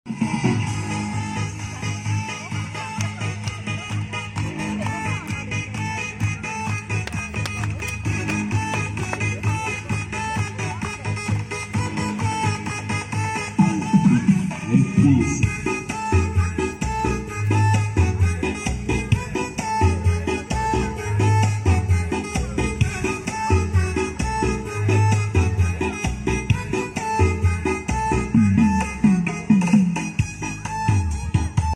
Latihan sek bolo persiapan karnaval